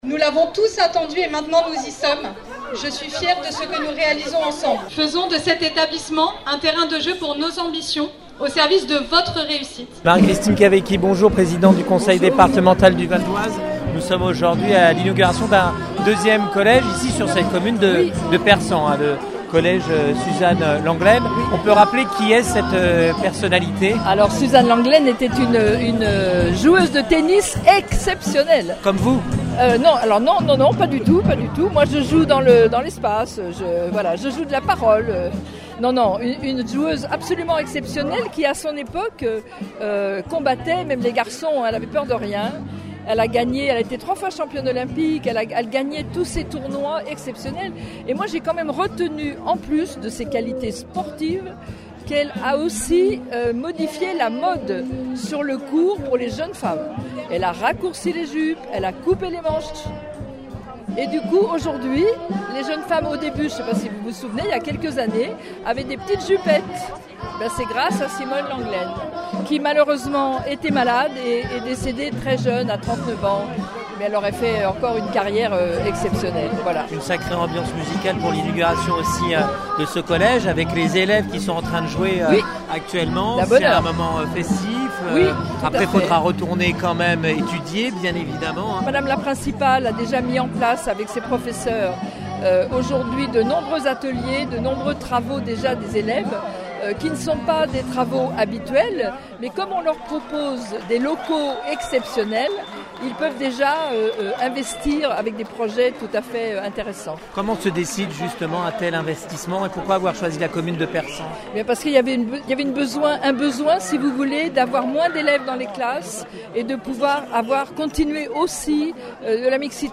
Vous pouvez également écouter le podcast réalisé à cette occasion par IDFM radio le Mercredi 2 Avril :
ITW-College-PERSAN-BON.mp3